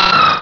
Cri de Galekid dans Pokémon Rubis et Saphir.